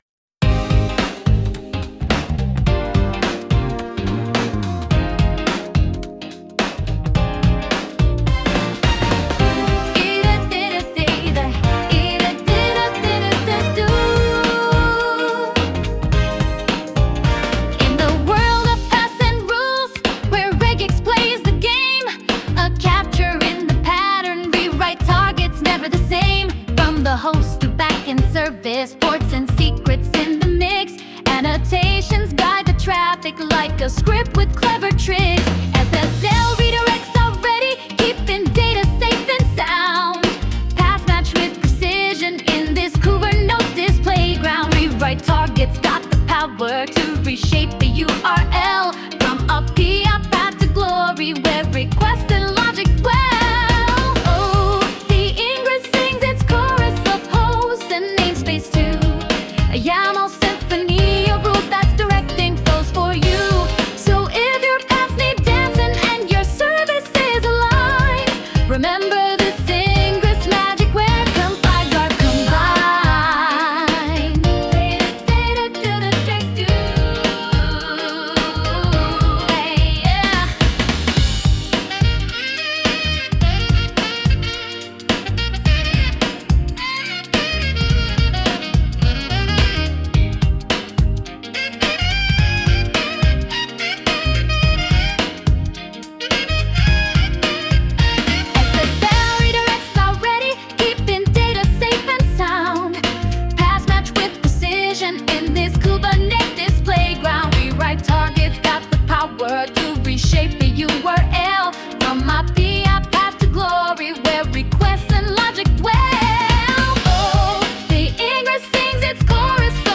この記事を歌う